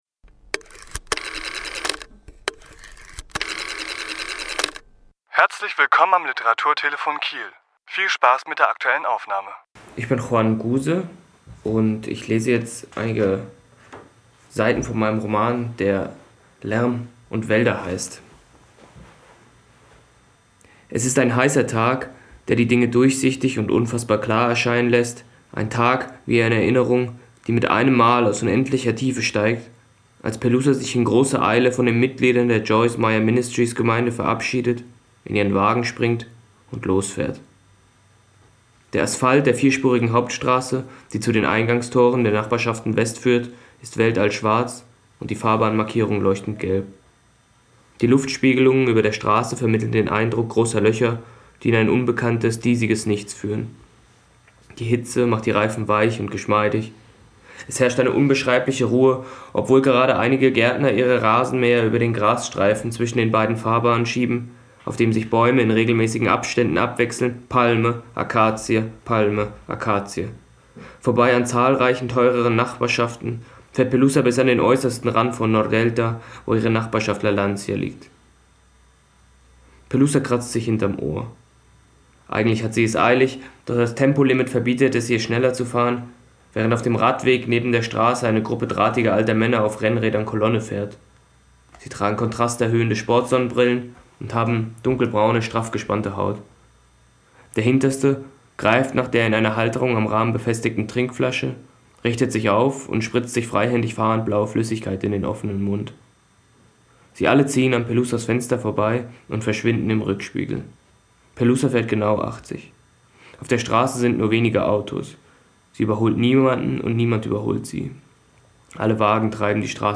Autor*innen lesen aus ihren Werken
Die Aufnahme entstand im Rahmen der LeseLounge im Literaturhaus Schleswig-Holstein am 2.12.2015. http